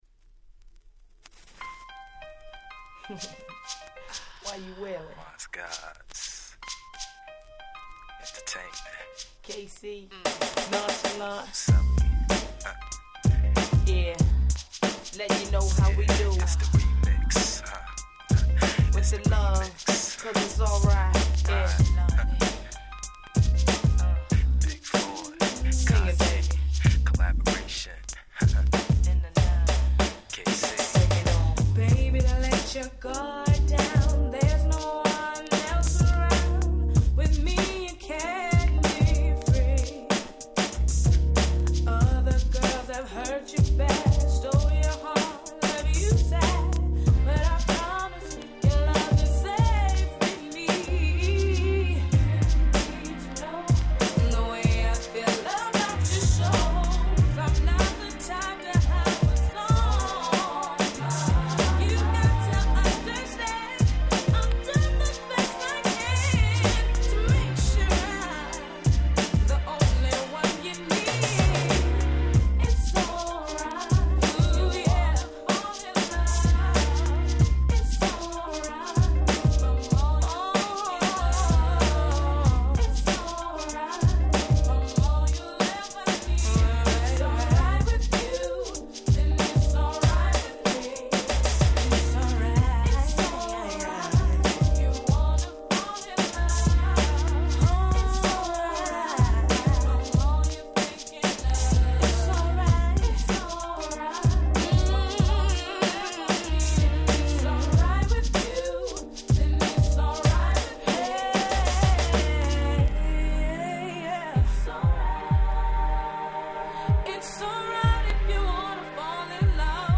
96' Very Nice Hip Hop Soul !!
ヒップホップソウル